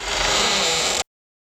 Roland.Juno.D _ Limited Edition _ GM2 SFX Kit _ 19.wav